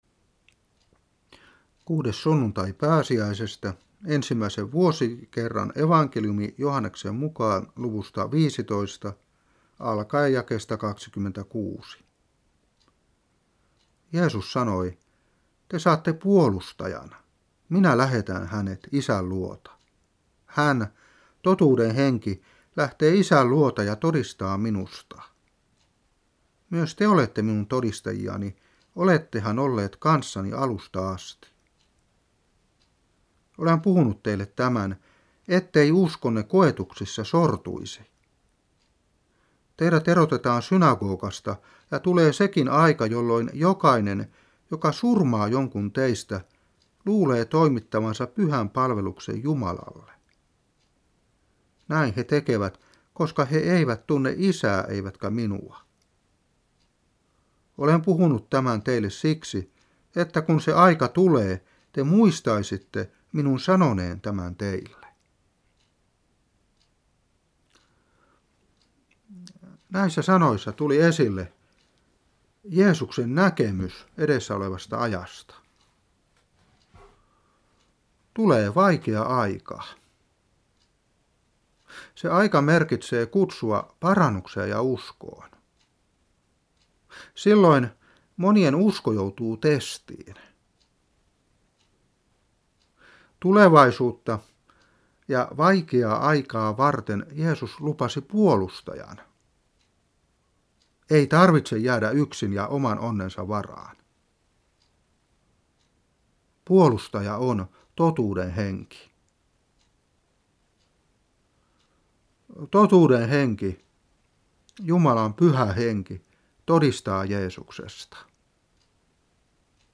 Saarna 2007-5.